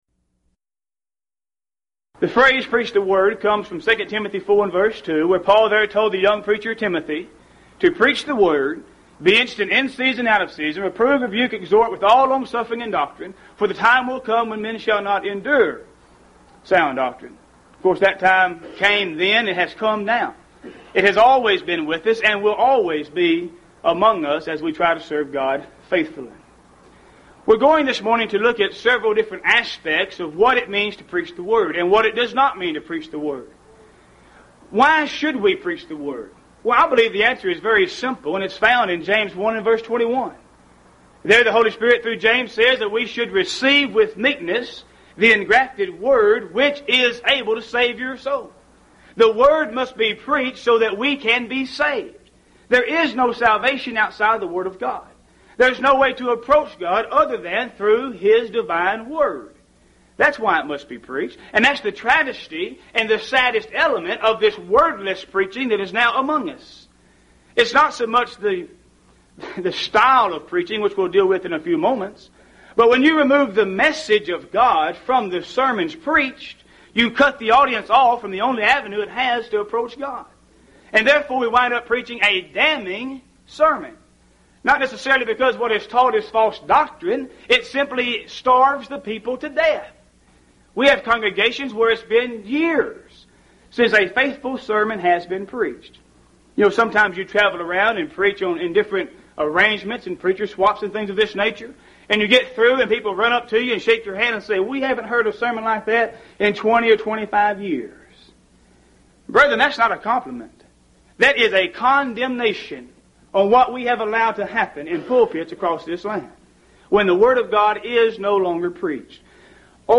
Series: Lubbock Lectures Event: 1st Annual Lubbock Lectures